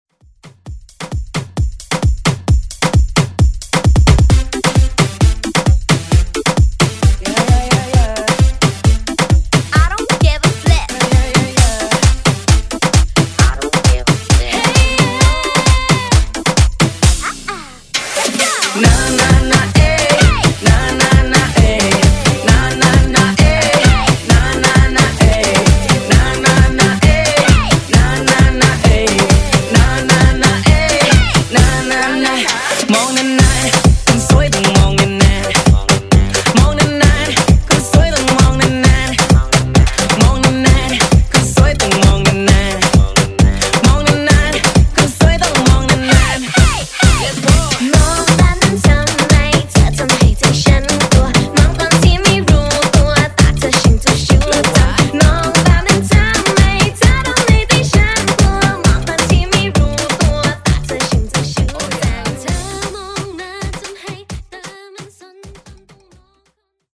70s, Pop, Rock
Genre: 70's
BPM: 122